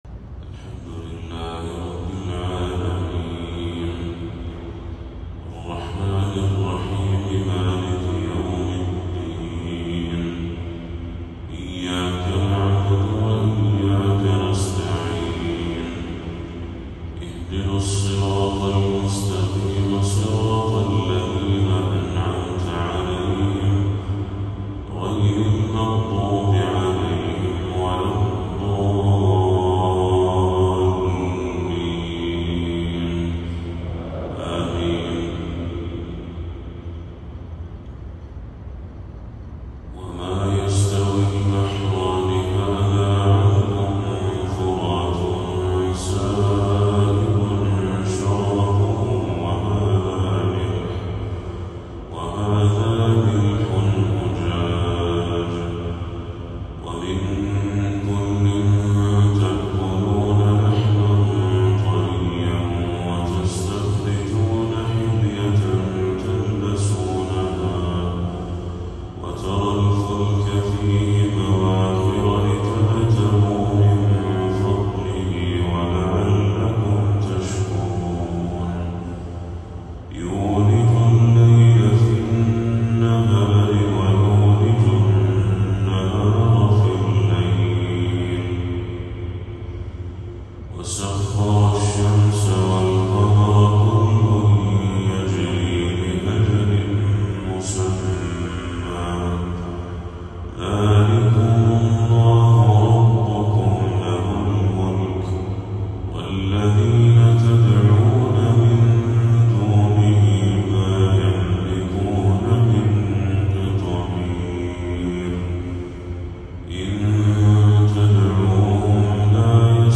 تلاوة آسرة من سورة فاطر للشيخ بدر التركي | فجر 4 ربيع الأول 1446هـ > 1446هـ > تلاوات الشيخ بدر التركي > المزيد - تلاوات الحرمين